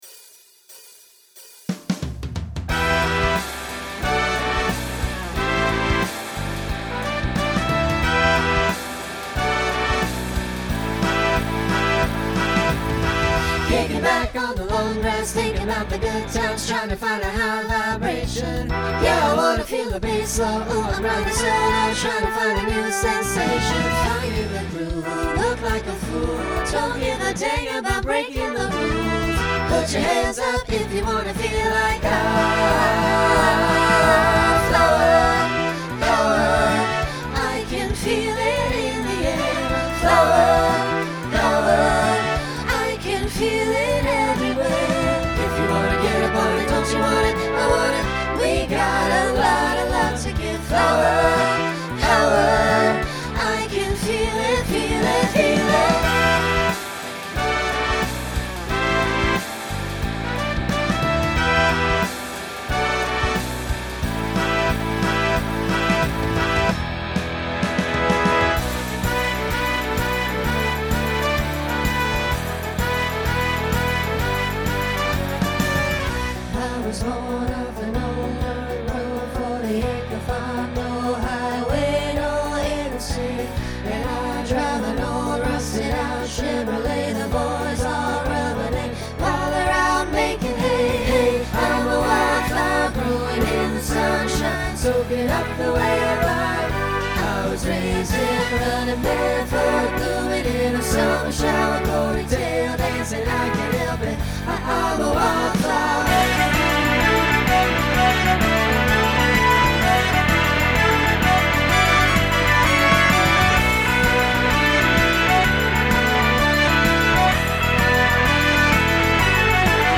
Genre Country , Rock
Transition Voicing SATB